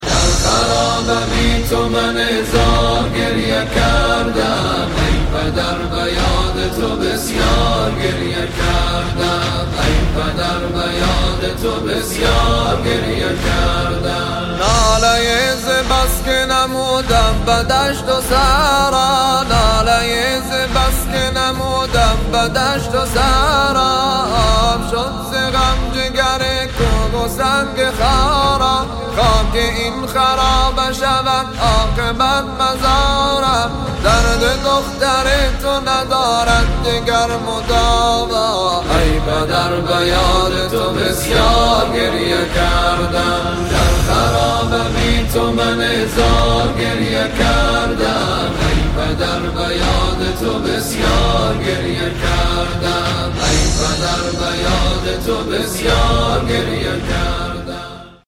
Ya Ruqayya 💔 In the ruins of Sham you are still in the remembrance of your father, Hussein! 😭🥀 Noha